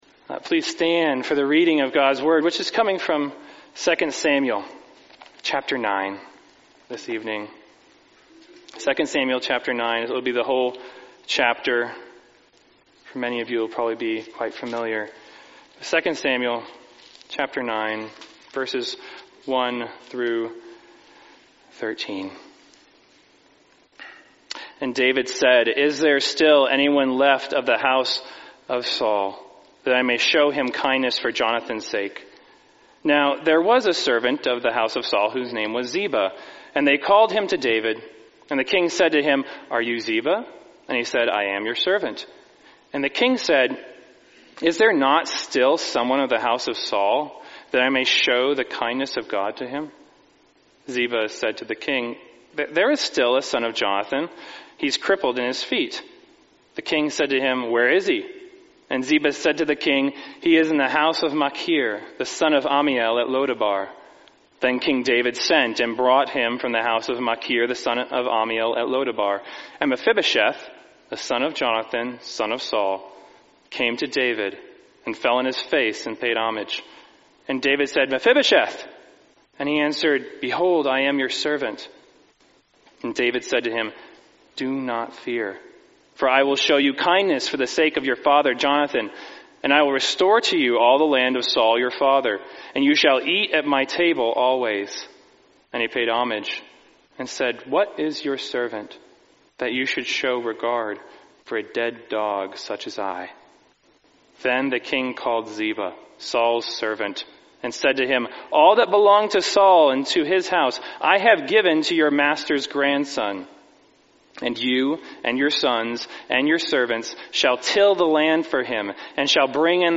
(Guest preacher)